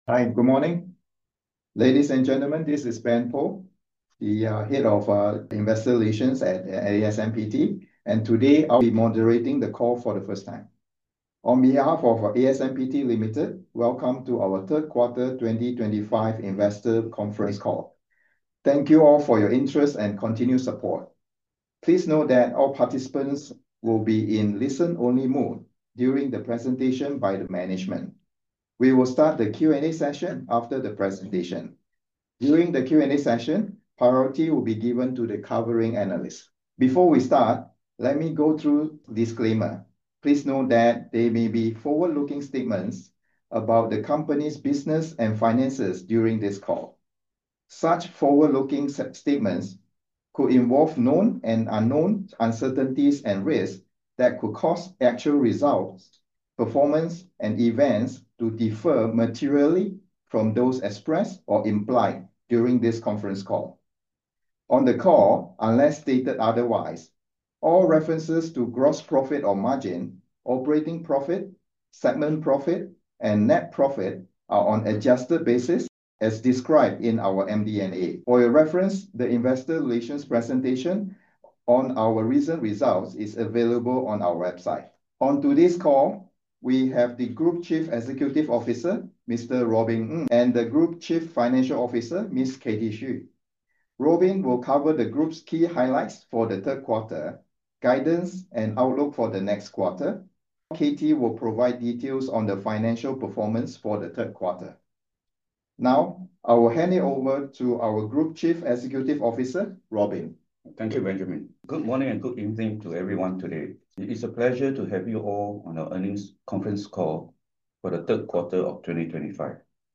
asmpt_q3_fy2025_investor_conference_call_audio.mp3